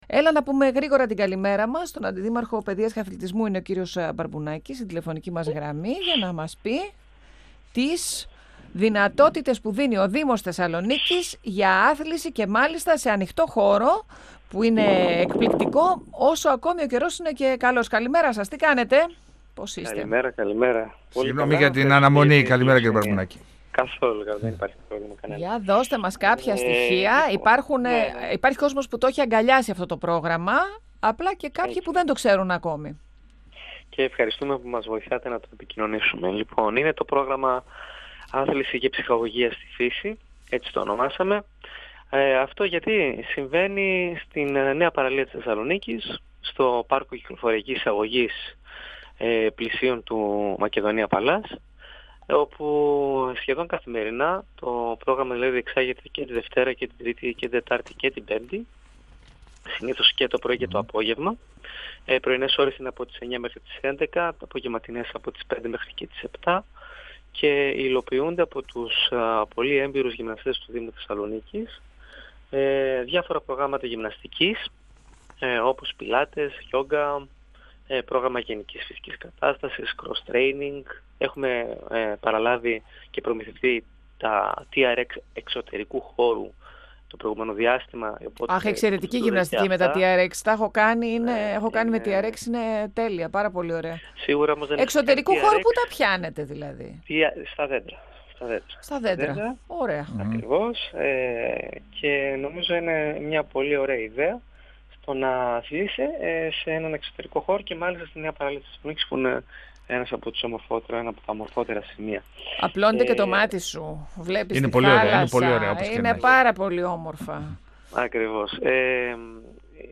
Ο αντιδήμαρχος Εκπαίδευσης και Αθλητισμού, Αλέξανδρος Μπαρμπουνάκης στον 102FM του Ρ.Σ.Μ. της ΕΡΤ3